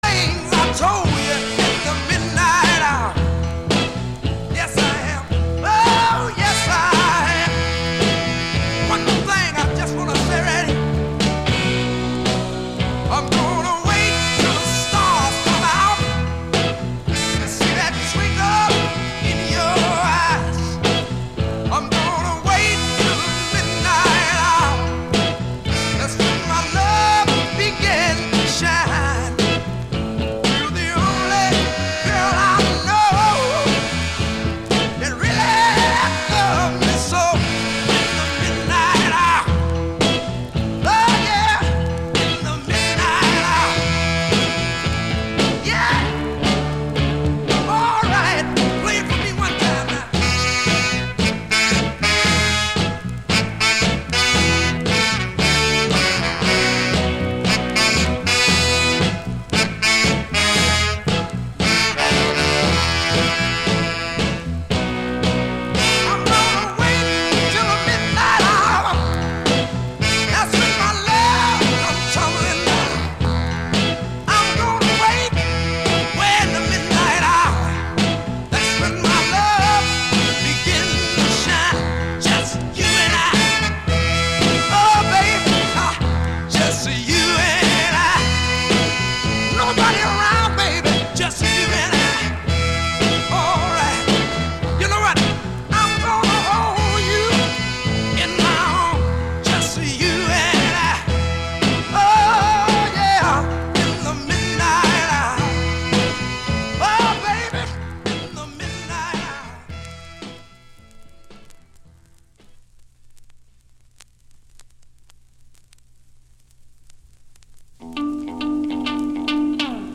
Like a mix-tape on your radio!